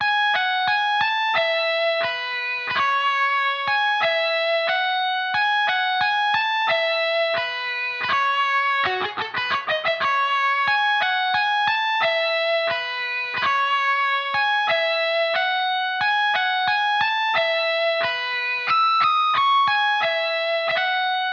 描述：F小调的主音吉他合成器。歪曲。与原声吉他的分层 6
Tag: 90 bpm RnB Loops Synth Loops 3.59 MB wav Key : Fm Ableton Live